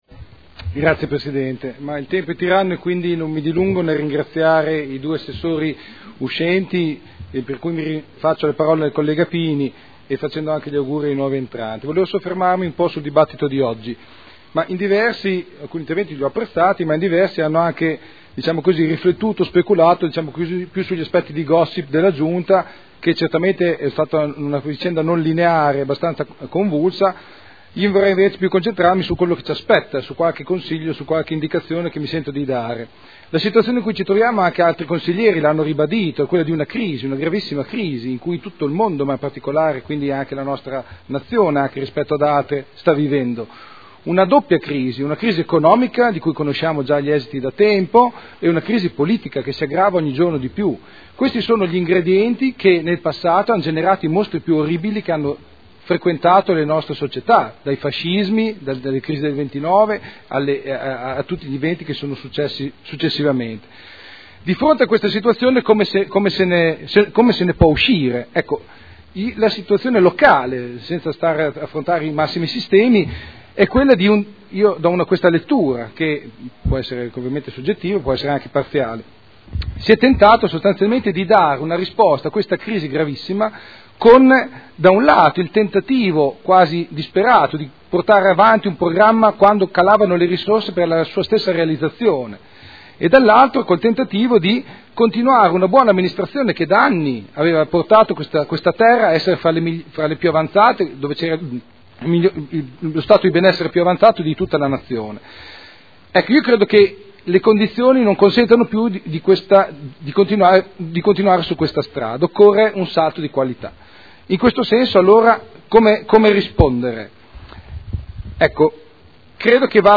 Dibattito su comunicazione del Sindaco sulla composizione della Giunta.